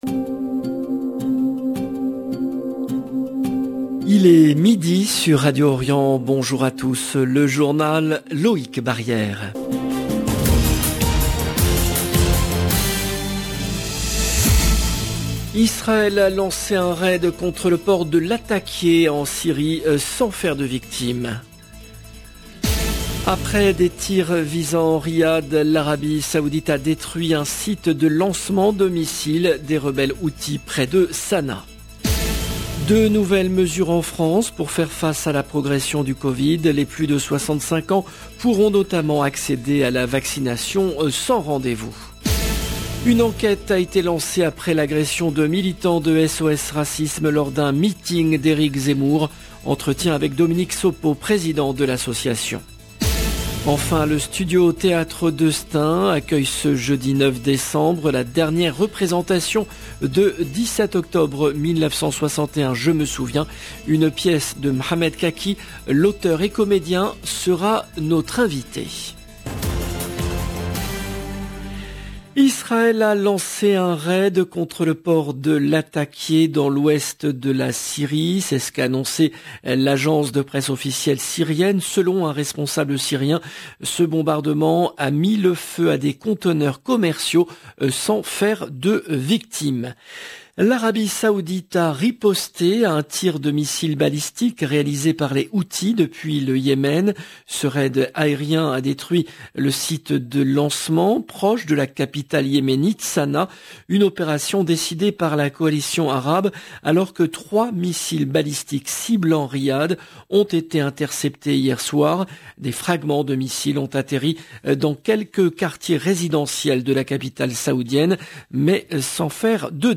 LE JOURNAL EN LANGUE FRANCAISE DE MIDI DU 7/12/21
Une enquête a été lancée après l’agression de militants de Sos Racisme lors d’un meeting d’Eric Zemmour. Entretien